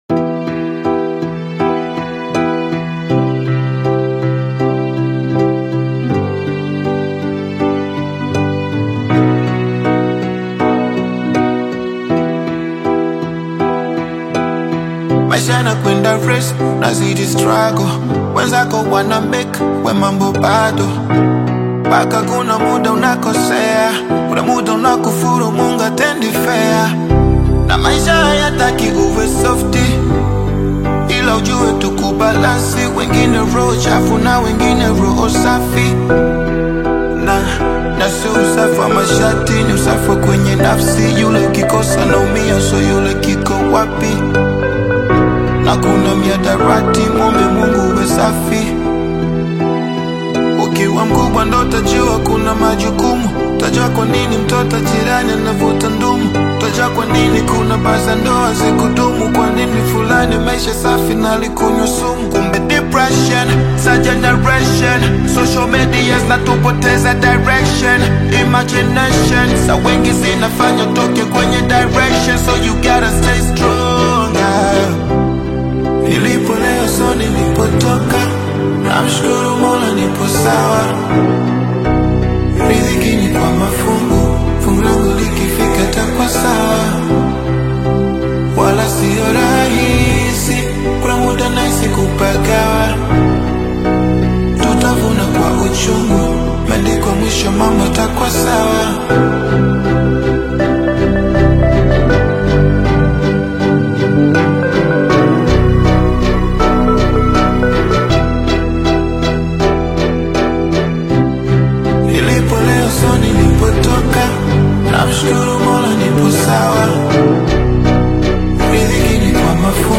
a smooth, well-arranged instrumental
heartfelt delivery